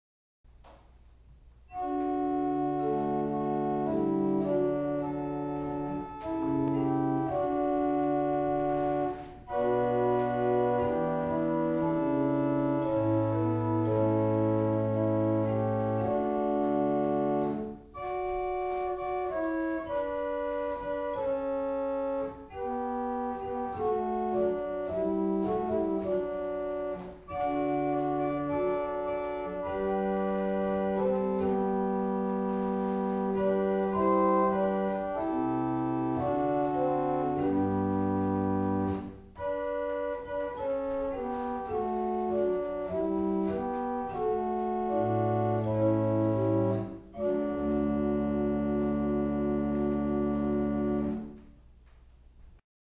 1802 Tannenberg Organ
Hebron Lutheran Church - Madison, VA
The Principal dulcis 8' has a stopped Quintadena bass and from tenor c, some of the pipes are in the facade.
an improvisation on the Principal dulcis 8'